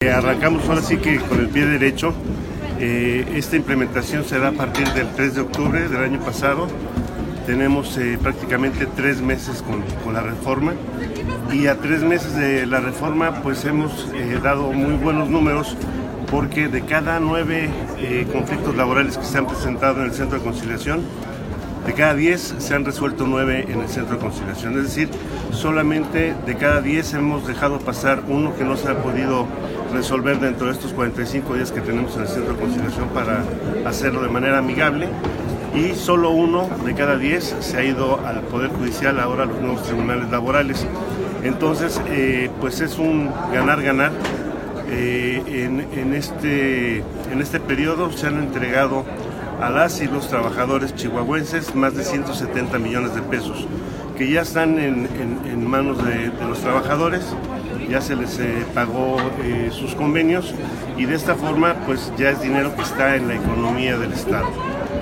Así lo dio a conocer el secretario del Trabajo y Previsión Social, Diodoro José Siller Argüello quien comentó que se han entregado cerca de 170 millones de pesos a los trabajadores como parte de los convenios a los que se llegaron y cada caso se logra resolver en los primeros 25 días a partir de que se interpone la queja.